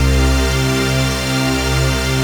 DM PAD2-31.wav